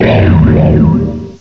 cry_not_cofagrigus.aif